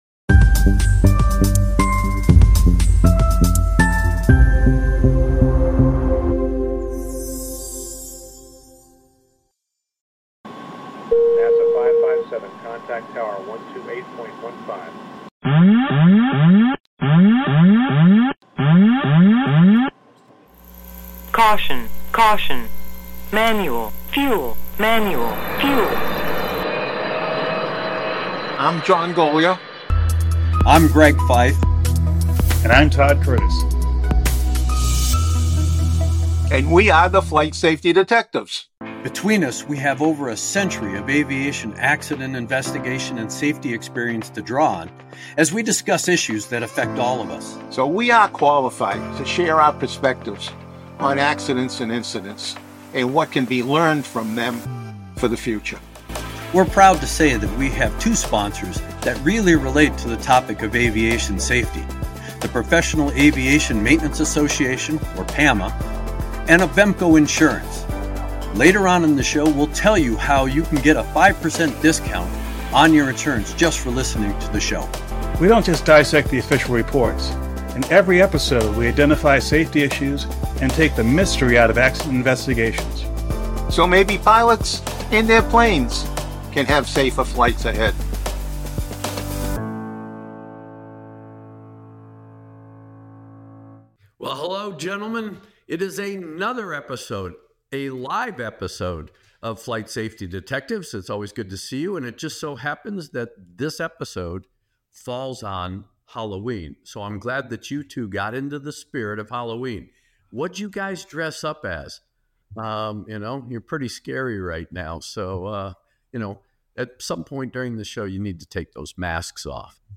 This recording of a livestream event covers many aviation safety topics, from designing aircraft for safety to mental health support for industry professionals to passenger responsibilities.